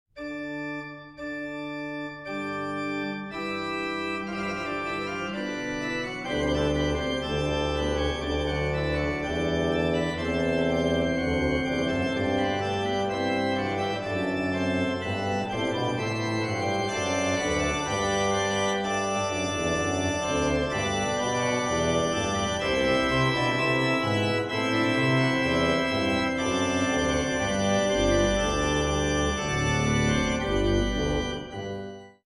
1980 Ahrend organ in Monash University, Melbourne
Organ